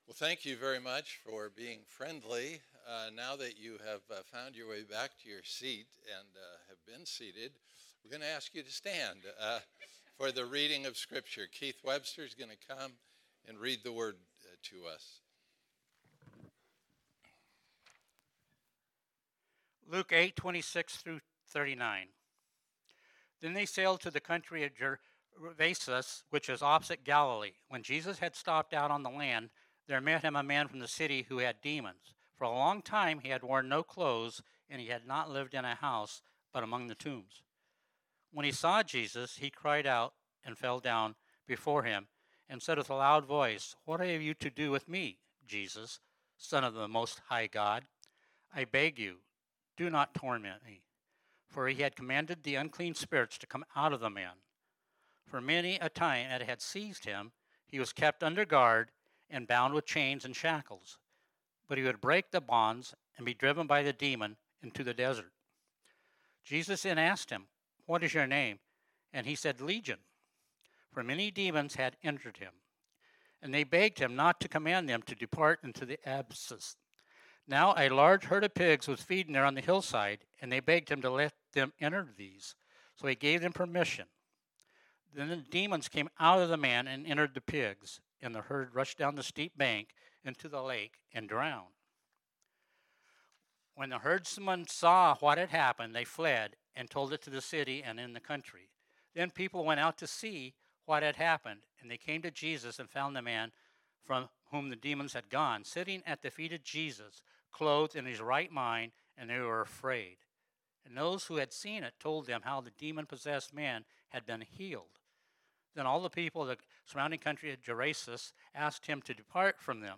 Series: The Gospel of Luke Type: Sermons